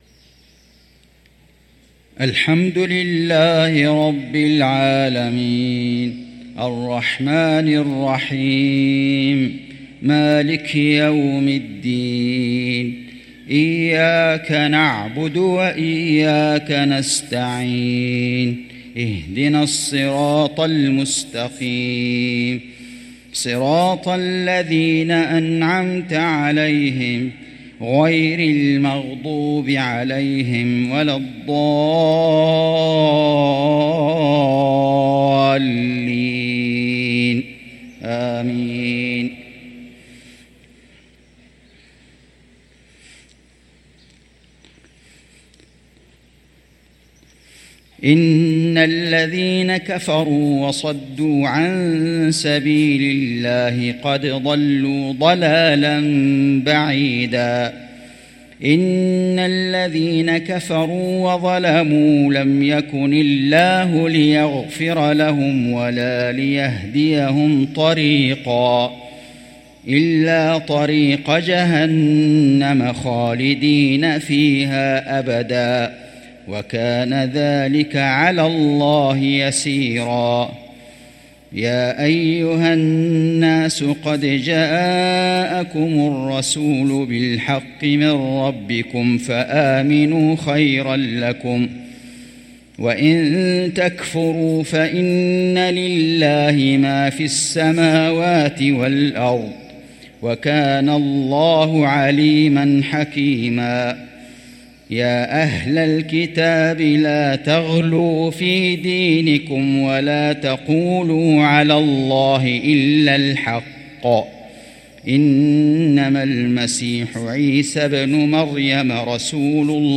صلاة العشاء للقارئ فيصل غزاوي 19 جمادي الآخر 1445 هـ
تِلَاوَات الْحَرَمَيْن .